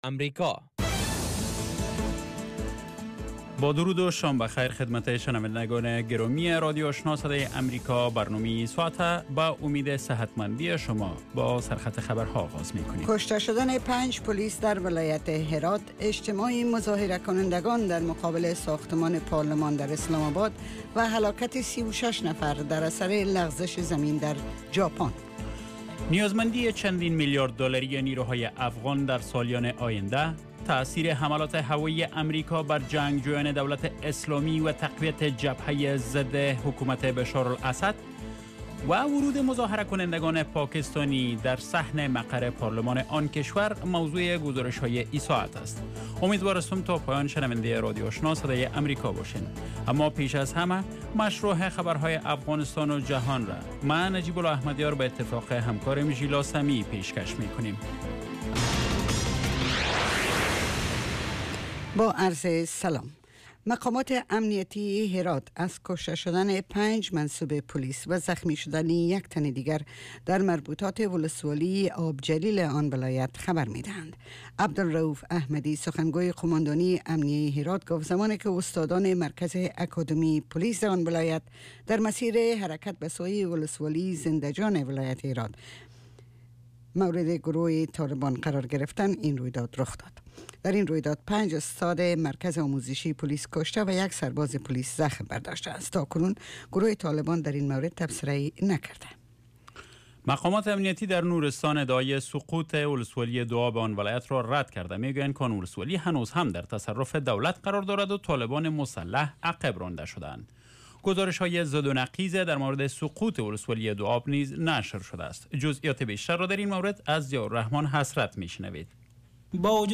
Radio evening first news half-hour show